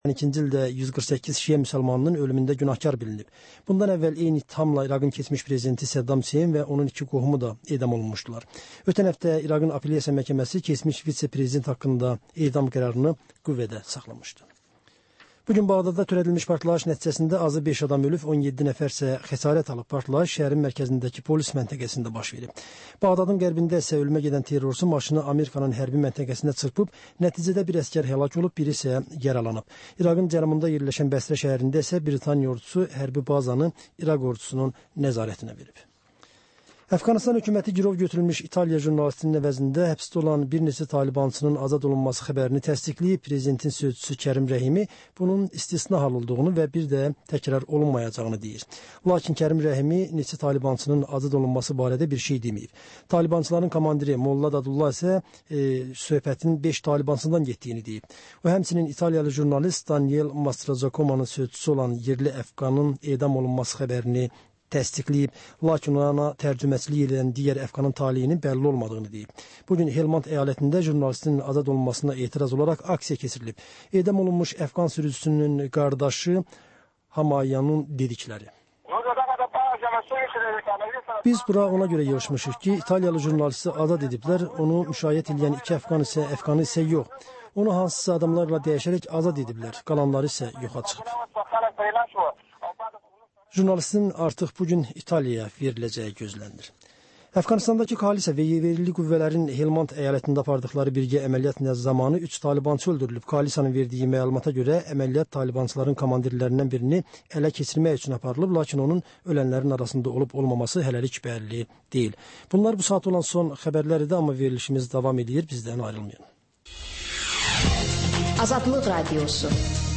Hadisələrin təhlili, müsahibələr, xüsusi verilişlər.